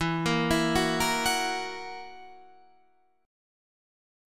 E Suspended 2nd Flat 5th